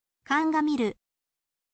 kangamiru